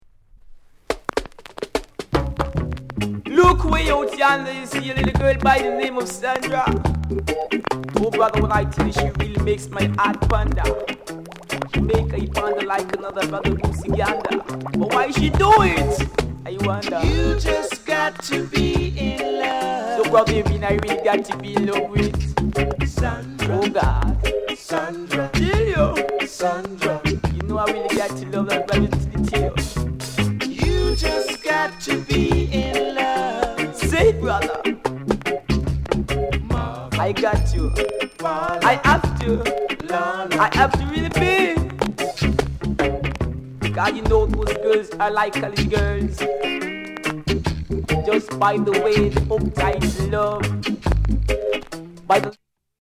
SKINHEAD INST